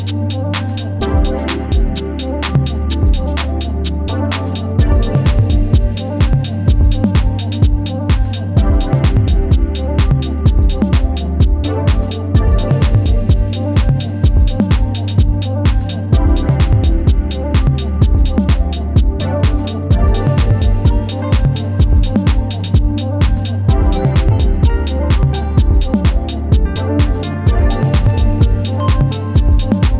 しっとりウォームでムーディーなフィーリングを基調とし、ジャジーな音色も程よくちりばめながら